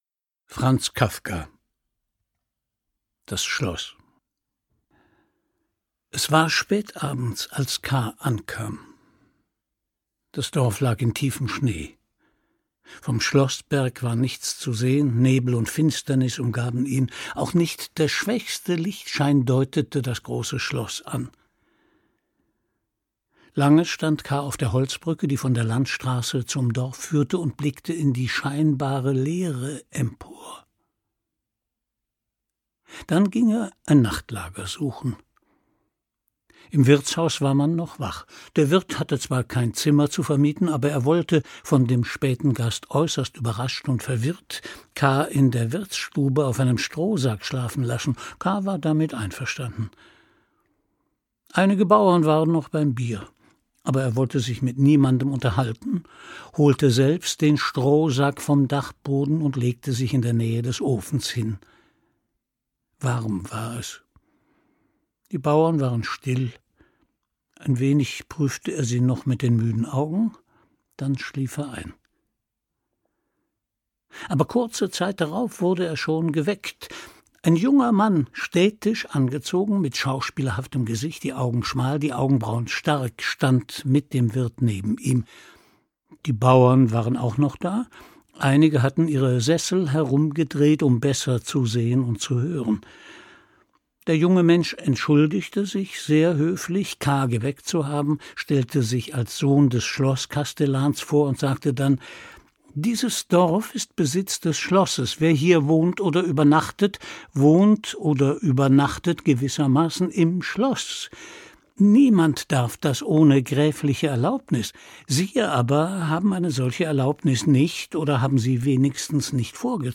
Das Schloß Franz Kafka (Autor) Christian Brückner (Sprecher) Audio Disc 2019 | 2.